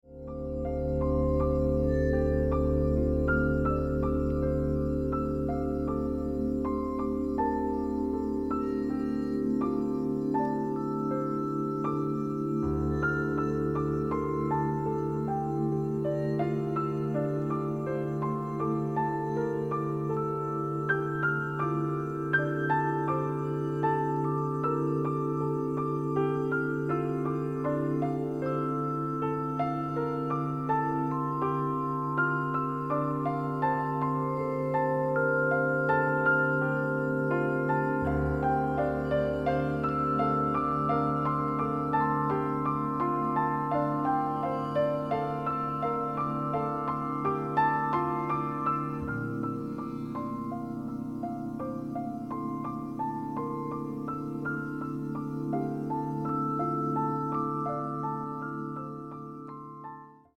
Piano, percussion, and electronic tape
piano
percussion .